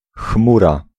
Ääntäminen
Ääntäminen France (Paris): IPA: [œ̃ ny.aʒ] Tuntematon aksentti: IPA: /nɥaʒ/ Haettu sana löytyi näillä lähdekielillä: ranska Käännös Ääninäyte Substantiivit 1. chmura {f} 2. obłok Suku: m .